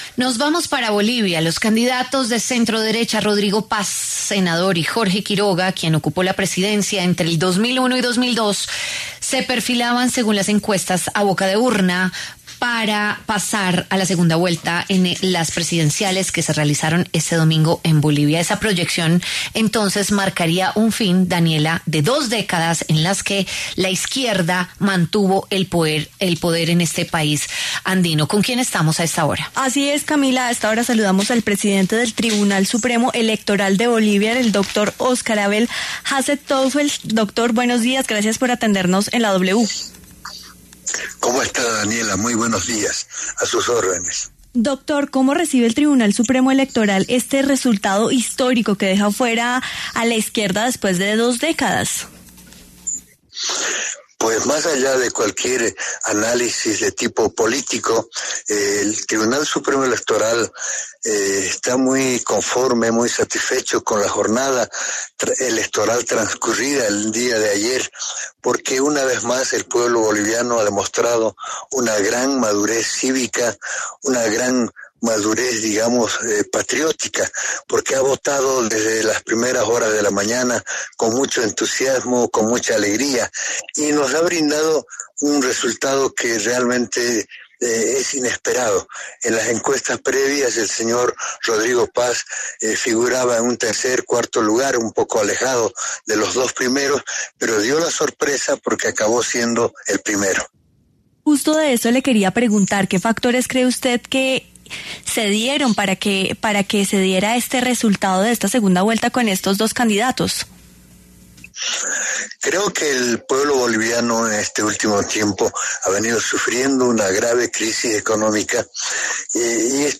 Óscar Abel Hassenteufel, presidente del Tribunal Supremo Electoral de Bolivia, habló en La W sobre las recientes elecciones que tuvieron un resultado inédito con dos candidatos de derecha a la segunda vuelta presidencial.
Para hablar sobre el tema pasó por los micrófonos de La W el presidente del Tribunal Supremo Electoral de Bolivia, Oscar Abel Hassenteufel.